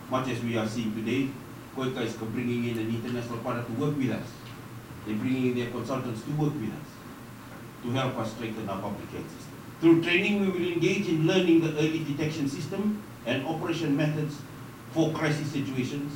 This has been admitted by the Permanent Secretary for Health Doctor James Fong while officiating during the opening ceremony for the Capacity Building Training for Disease Surveillance and Responses.
Permanent Secretary for Health Doctor James Fong.